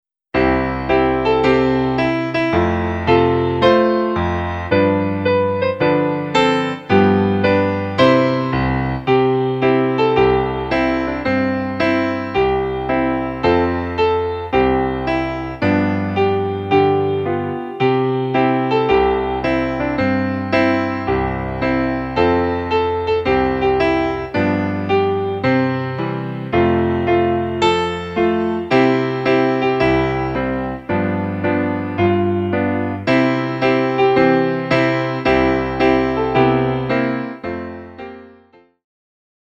Piano Solo - Intermediate